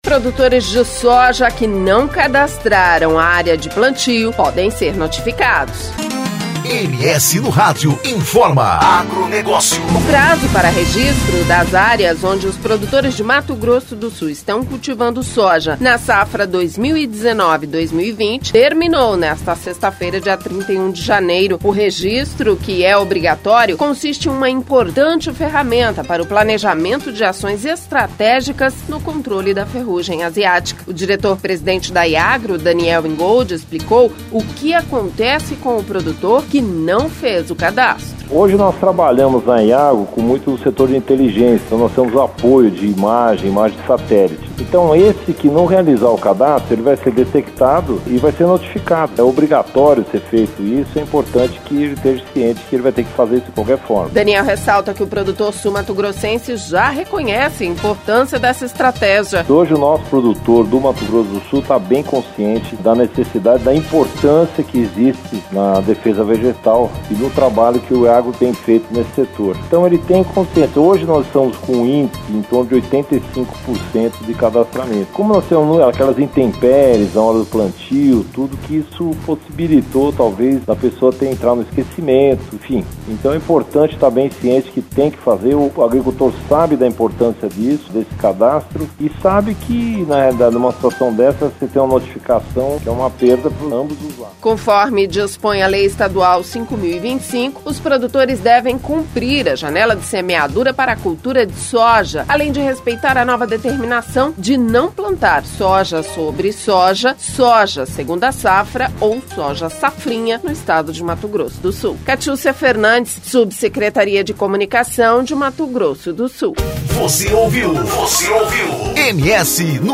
O Diretor Presidente da Iagro, Daniel Ingold, explicou o que acontece com o produtor que não fizer o cadastro.